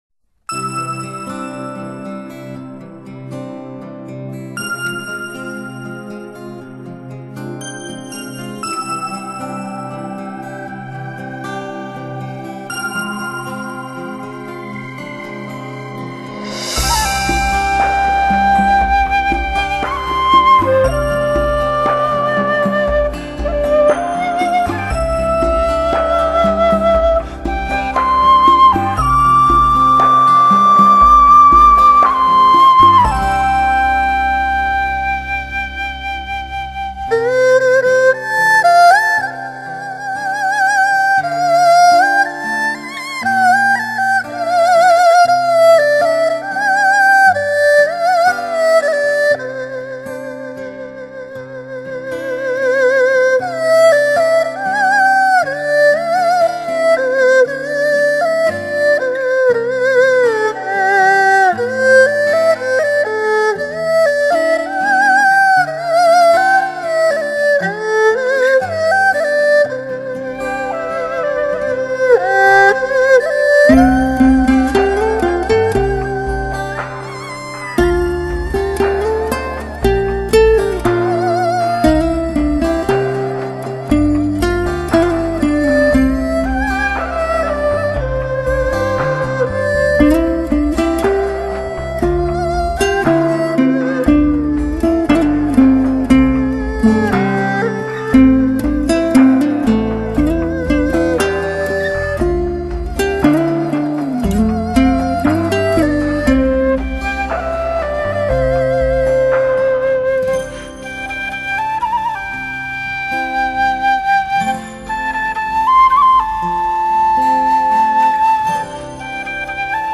二胡
吉他
笛子、箫、葫芦丝
小提琴
琵琶
古筝
大提琴
萨克斯
长笛
单簧管
双簧管